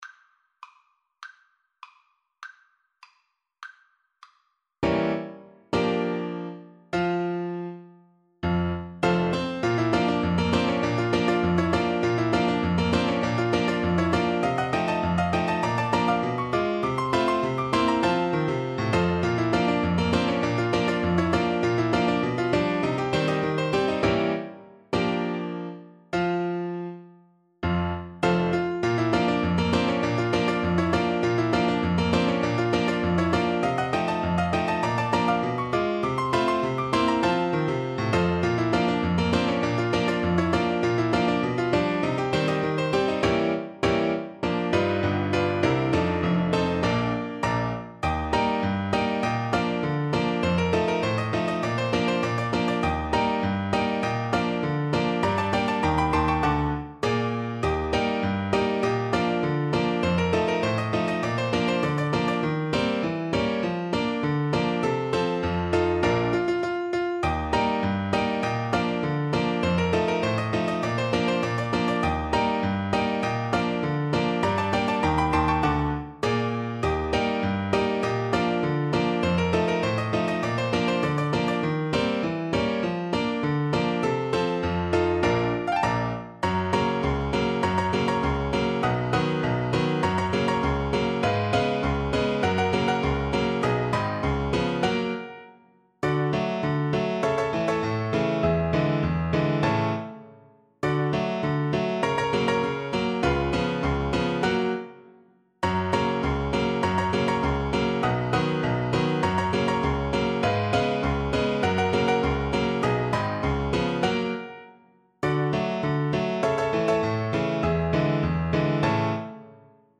• Unlimited playalong tracks
2/4 (View more 2/4 Music)
Jazz (View more Jazz Saxophone Music)